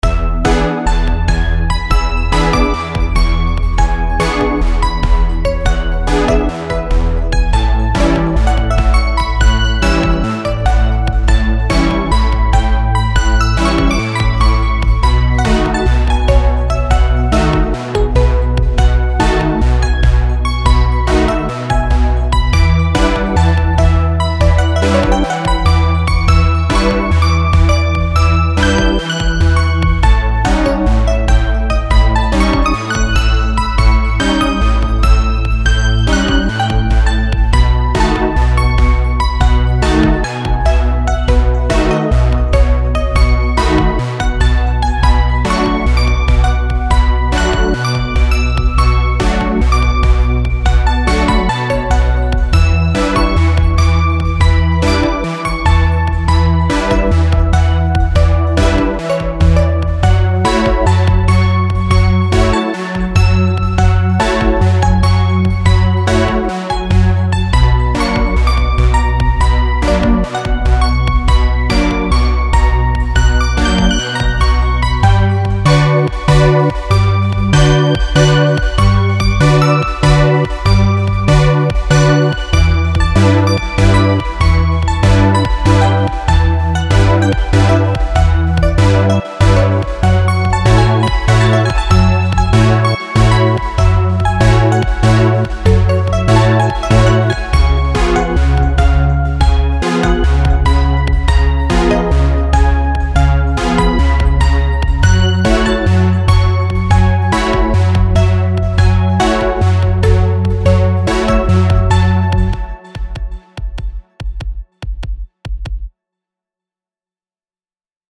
Mixolydian Heaven Electronic